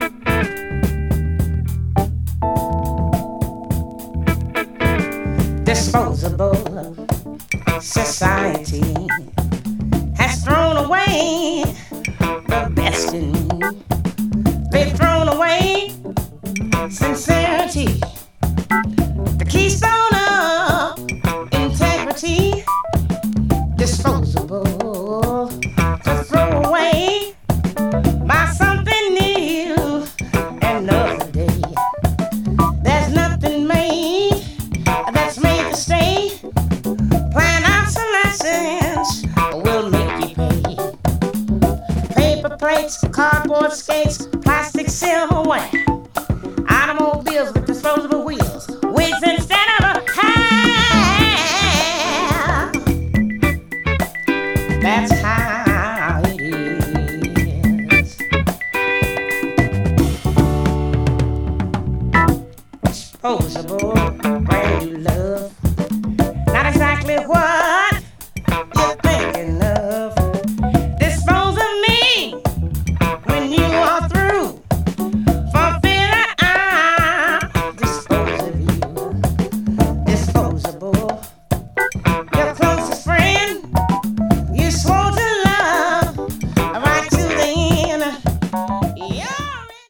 とにかく素晴らしい音質。
メリハルのある音質が秀逸な国内盤です。
blues jazz   jazz funk   jazz vocal   soul jazz